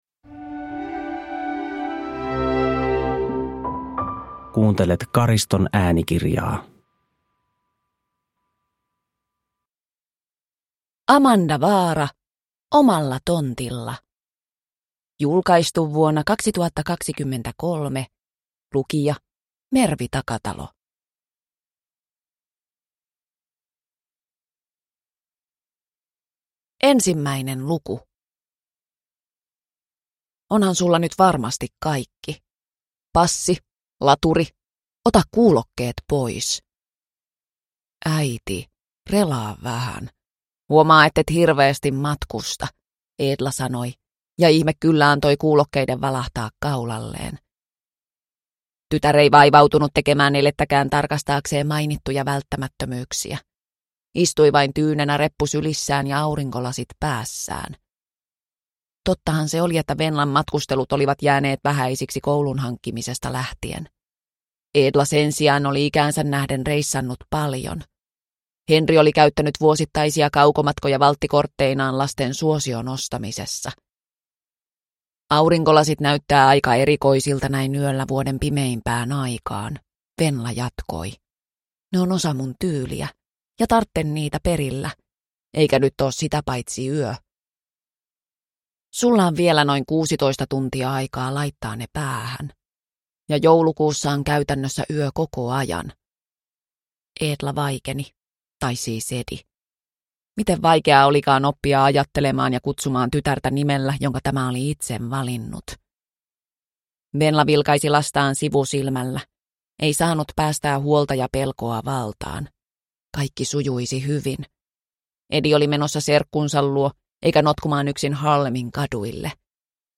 Omalla tontilla – Ljudbok – Laddas ner